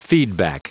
Prononciation du mot feedback en anglais (fichier audio)
Prononciation du mot : feedback